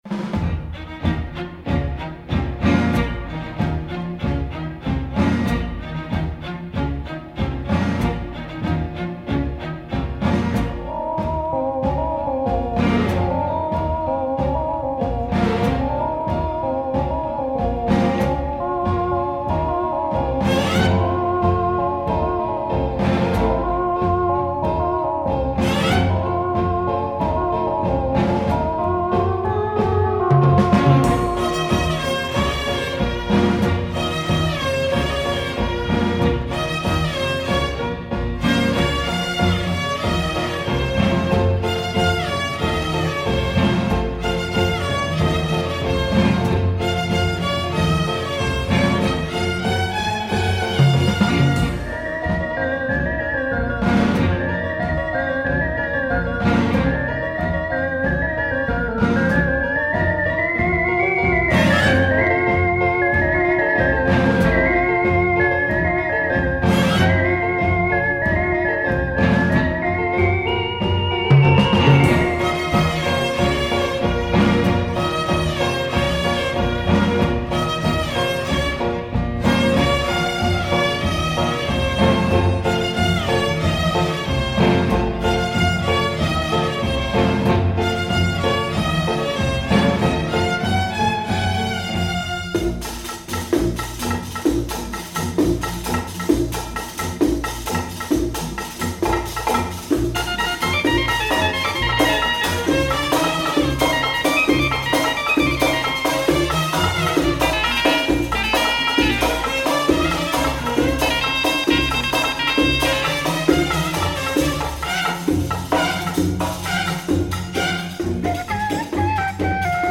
Organ and psych guitar !